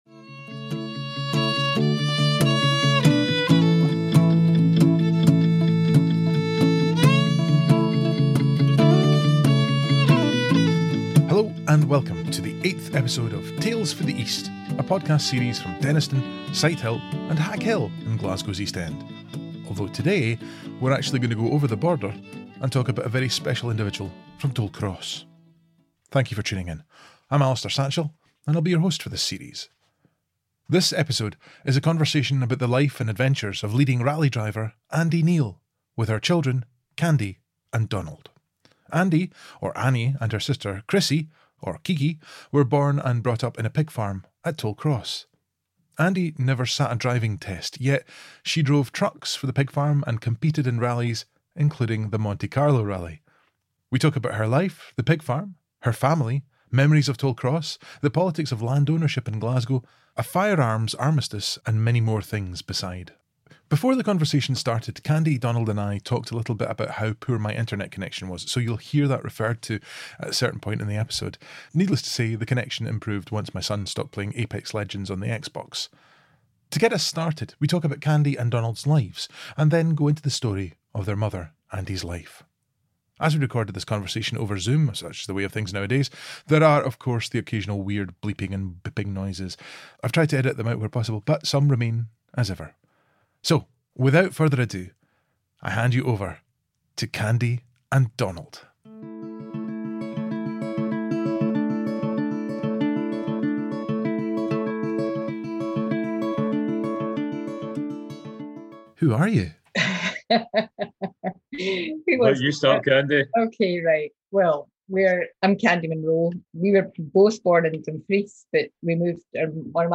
This episode is a conversation